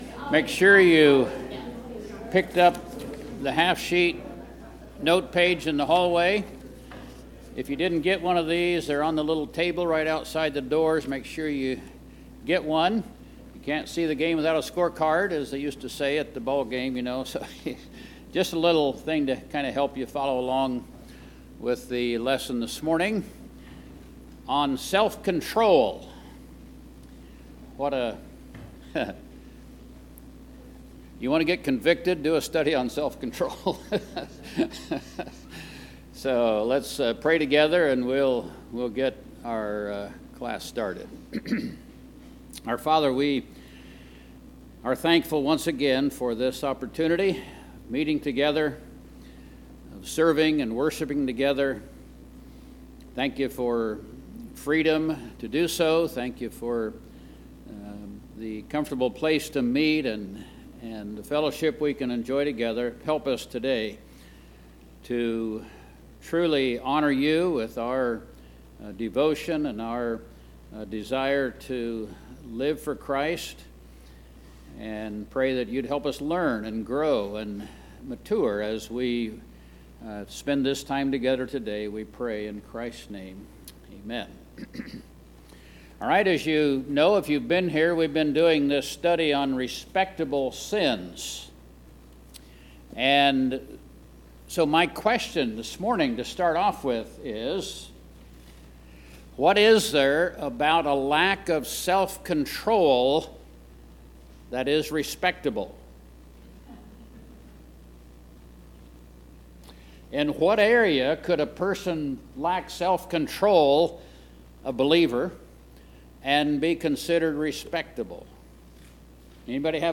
Respectable Sins Discussion, Biblical Self Control: An Essential Virtue | Westerville Bible Church